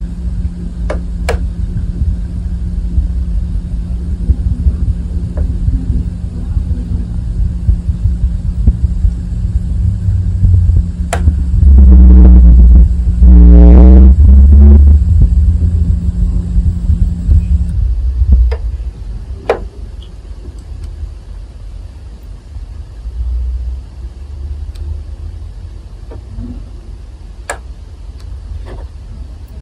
- mauvaise qualité du plastique intérieur => peu probable car les 3 UI ont des craquements (certes à des degrés différents)
forum consulter ce sujet Bruits UI climatiseur Daikin
J'ai des bruits dérangeants dans les UI de ma climatisation installée depuis l'automne.
La marque a exclu un problème de qualité du plastique car ça le fait sur 3 UI différentes (de manière plus ou moins régulière et forte selon les UI, mais elles le font toutes, même si elles sont éteintes alors qu'une autre fonctionne).